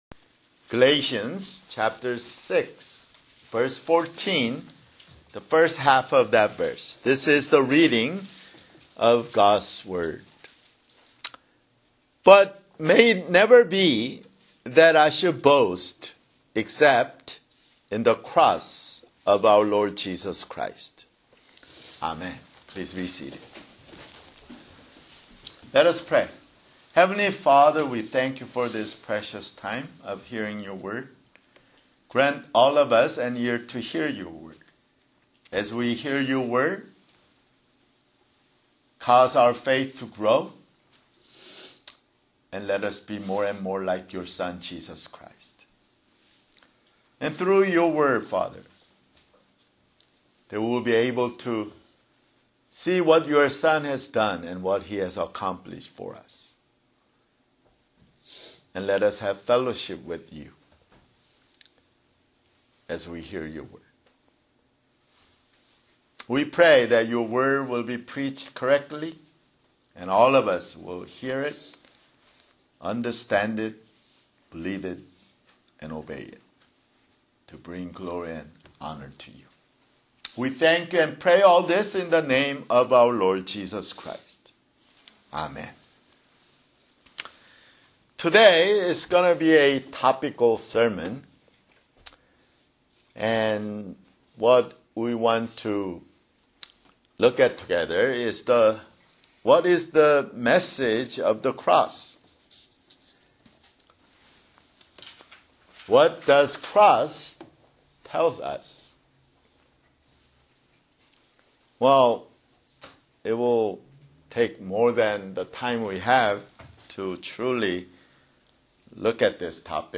[Sermon] Galatians 6:14